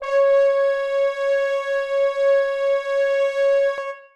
brass / horn-section / samples / C#5_ff.mp3
C#5_ff.mp3